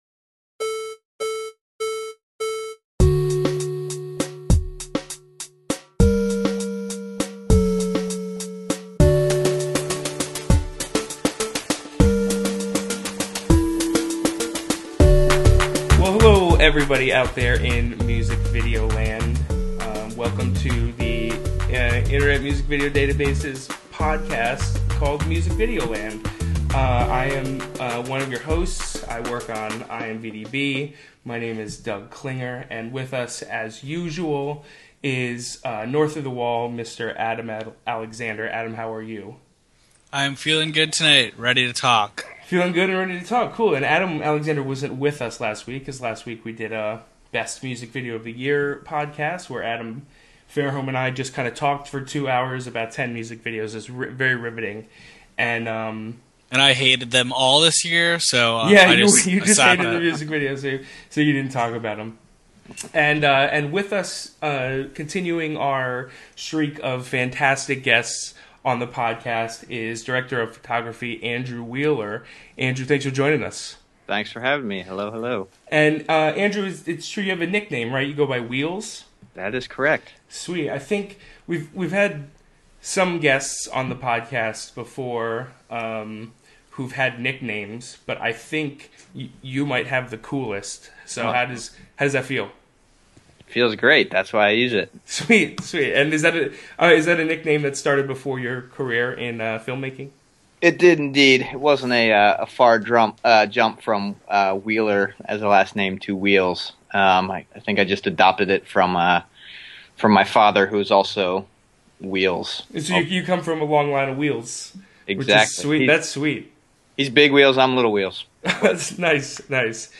A weekly conversation about music video news and new releases.